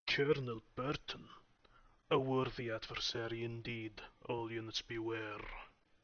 Subject description: My personal VO set   Reply with quote  Mark this post and the followings unread